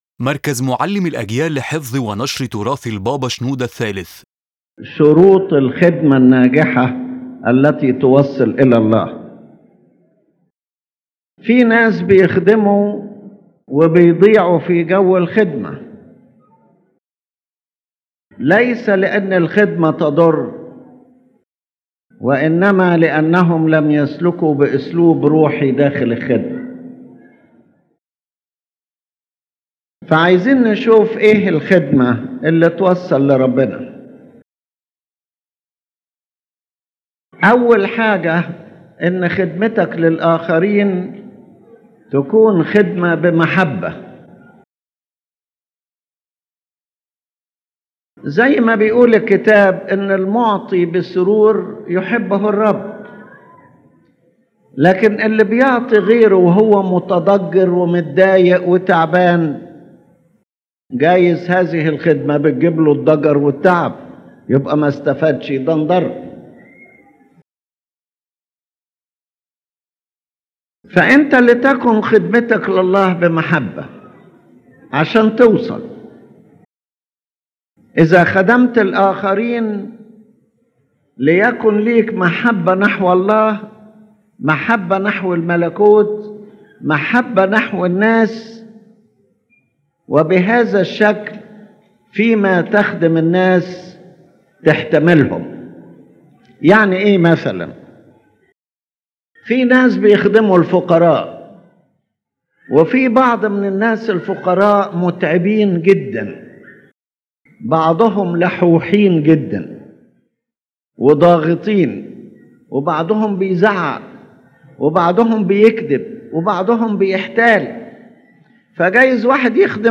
The lecture explains the conditions for the success of church and spiritual service, and shows that service alone is not enough unless practiced in the right spirit — love, patience, wisdom, and humility — so that it bears fruit and leads to God.